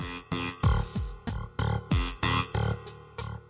描述：带打击乐器的翘曲键盘
Tag: 印章 贝司